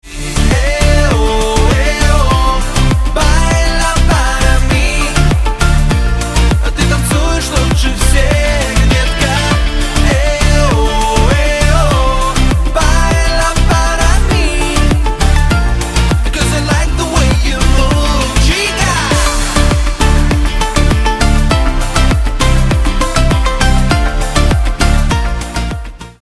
• Качество: 128, Stereo
поп
зажигательные
dance